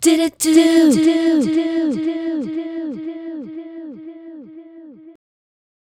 Eko Di Dit 120-E.wav